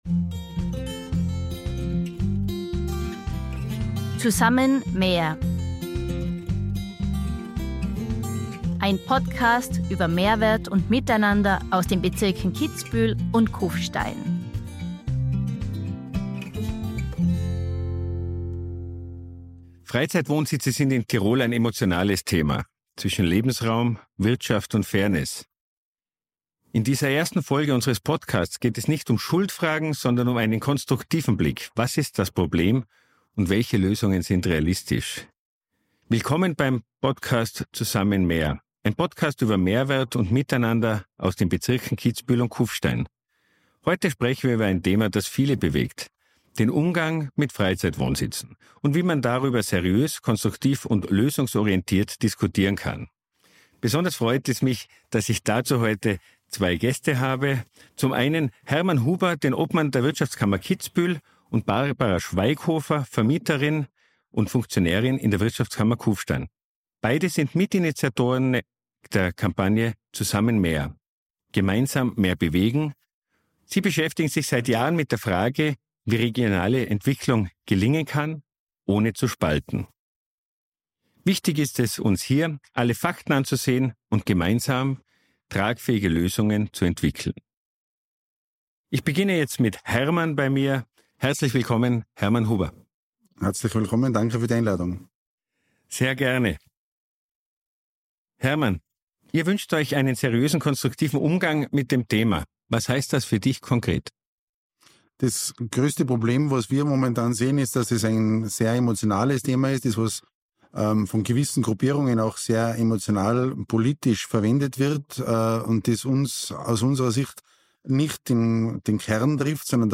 Ein Gespräch über Gastfreundschaft, gesellschaftlichen Zusammenhalt und die Frage, wie Tirol seine Zukunft gestalten kann – gemeinsam und lösungsorientiert.